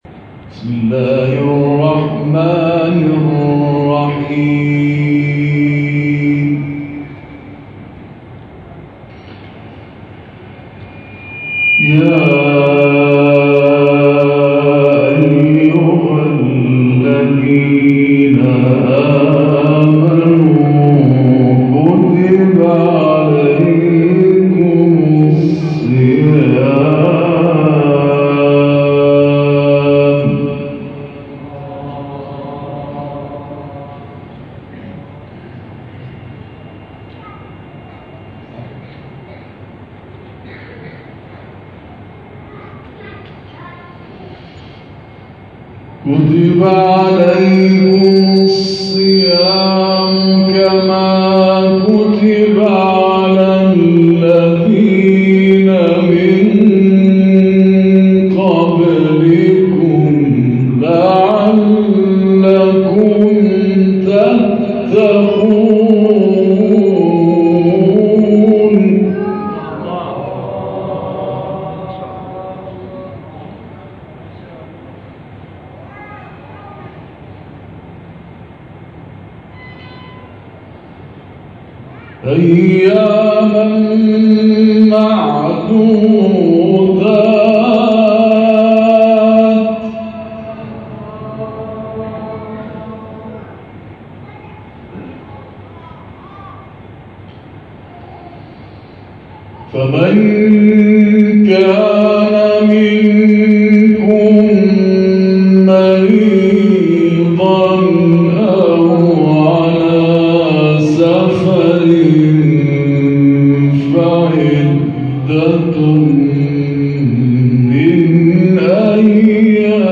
جدیدترین تلاوت